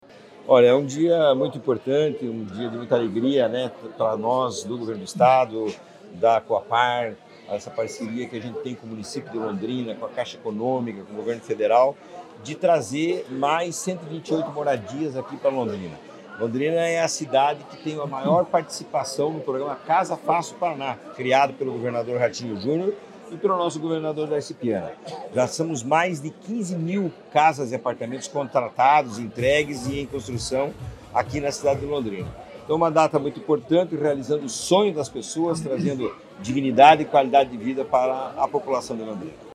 Sonora do diretor-presidente da Cohapar, Jorge Lange, sobre a entrega de residencial com 128 apartamentos para atender famílias de Londrina